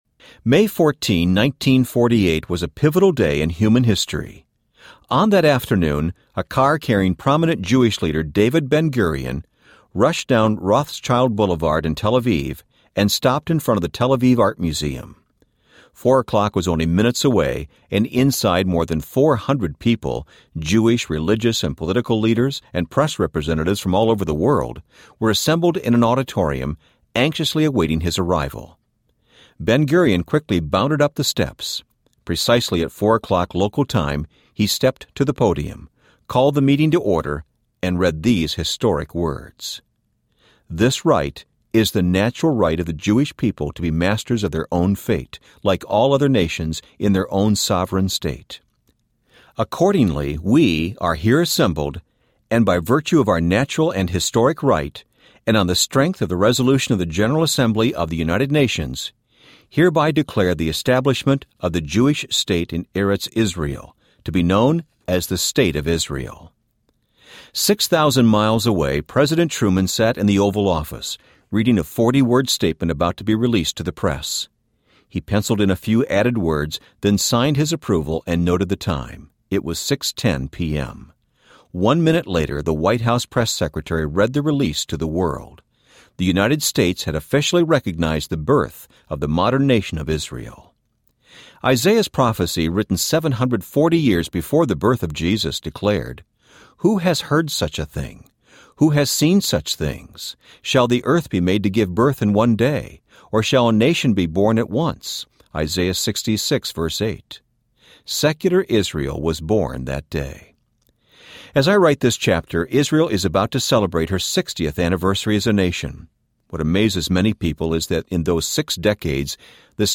What in the World is Going On? Audiobook